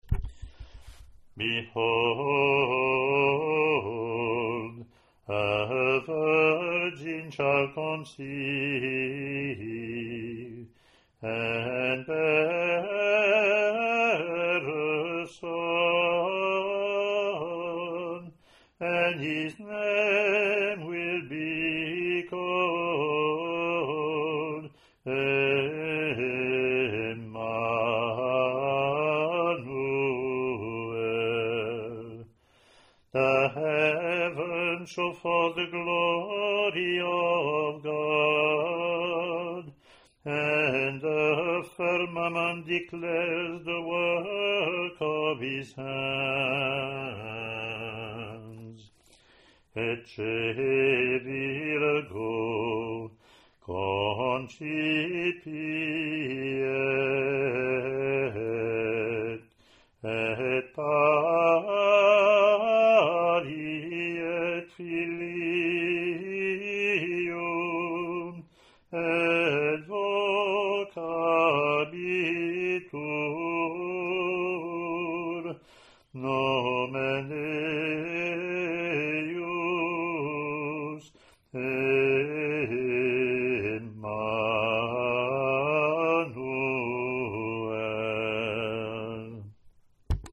English antiphon – English verse – Latin antiphon + verses)